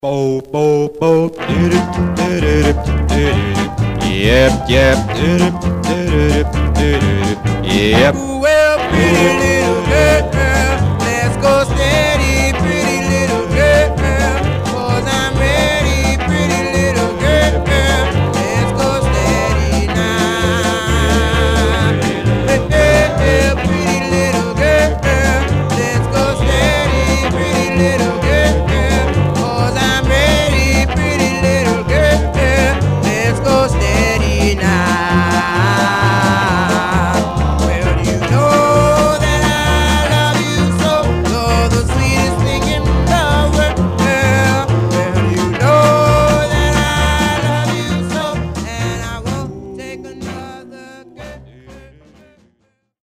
Stereo/mono Mono
Male Black Groups